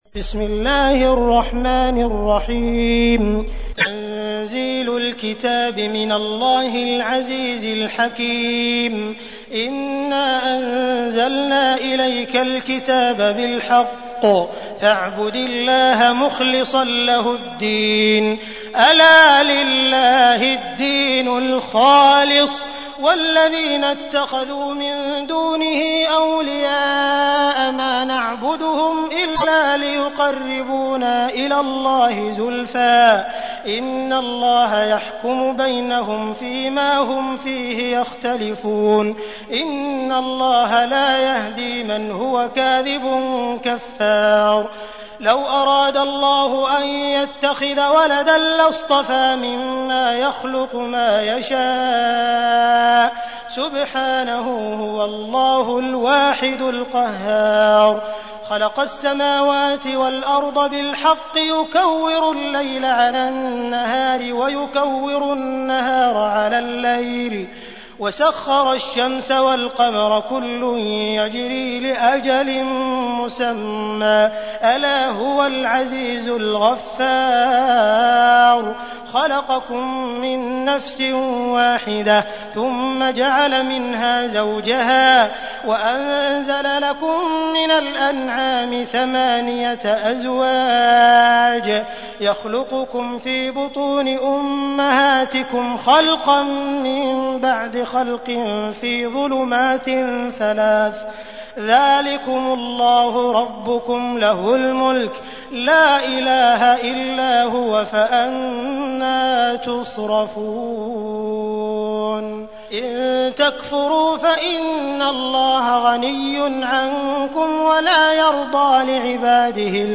تحميل : 39. سورة الزمر / القارئ عبد الرحمن السديس / القرآن الكريم / موقع يا حسين